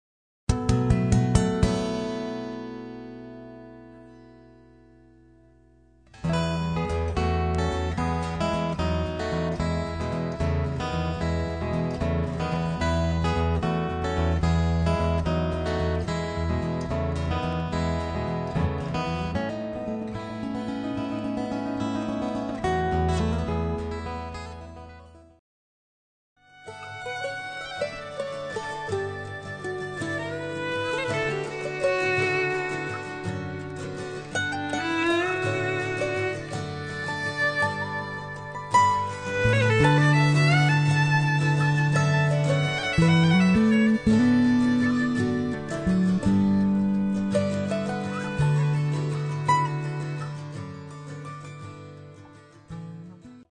Contemporary Folk  -